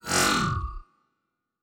pgs/Assets/Audio/Sci-Fi Sounds/Doors and Portals/Teleport 4_2.wav at master
Teleport 4_2.wav